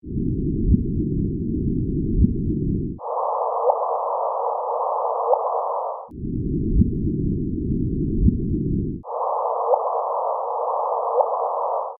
These chirps are gravitational waves converted to audible sounds. The faint thump matches the gravitational waves’ frequencies. The louder chirp is a higher frequency better suited to human ears.